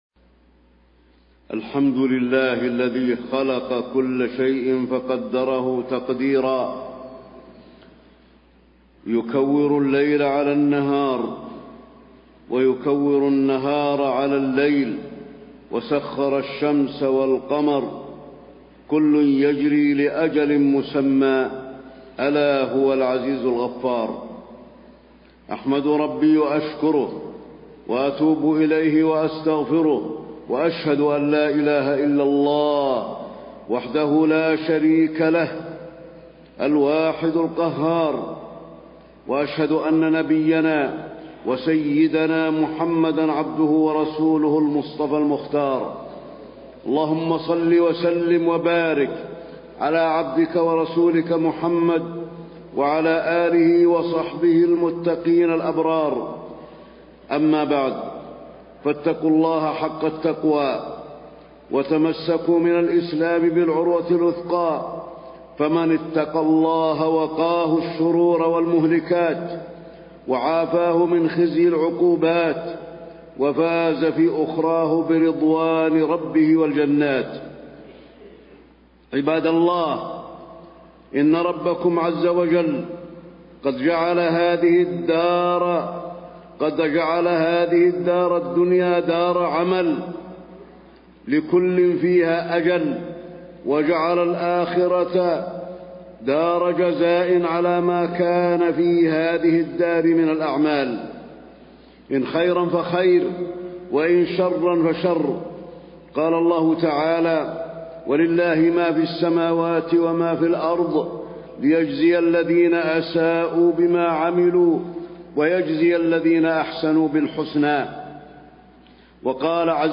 تاريخ النشر ١٧ رجب ١٤٣٥ هـ المكان: المسجد النبوي الشيخ: فضيلة الشيخ د. علي بن عبدالرحمن الحذيفي فضيلة الشيخ د. علي بن عبدالرحمن الحذيفي اغتنام العمر في الطاعات